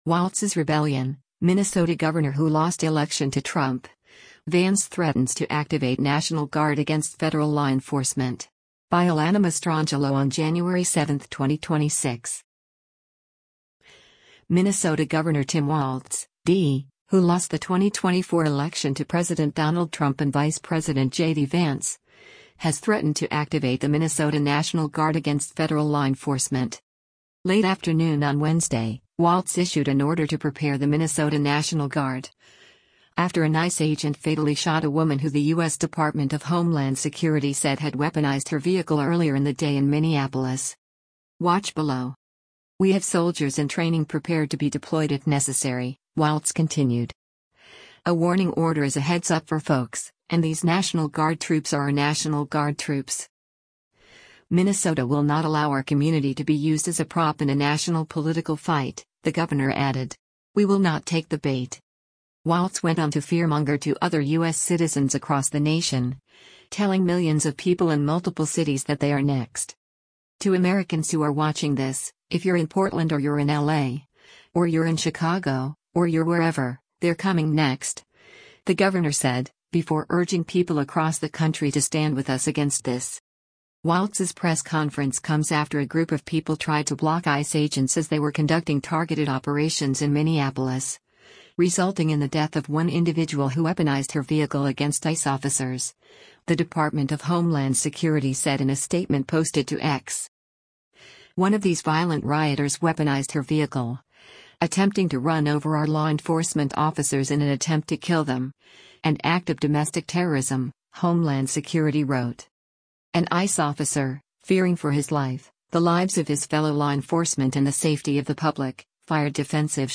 Minnesota Governor Tim Walz speaks during a press conference focused on Minnesota’s new